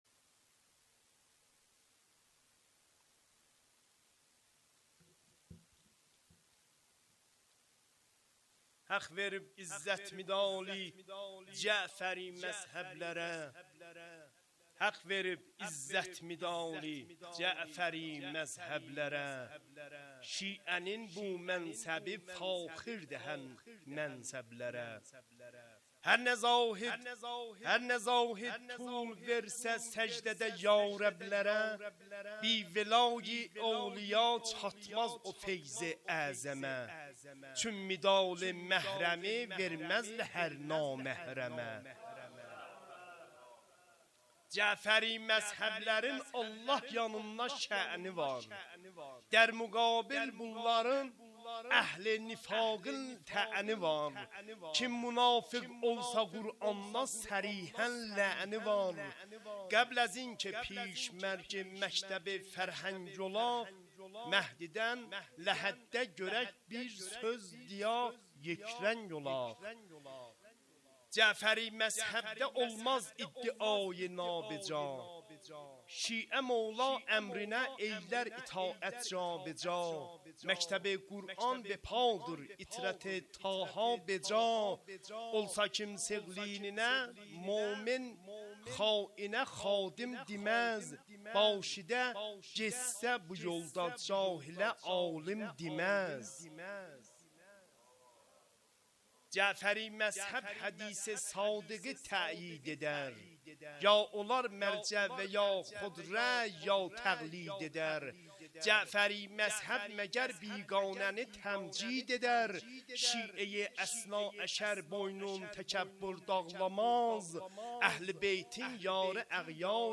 خیمه گاه - هیئت مادرم فاطمه (س)زنجان - شعرخوانی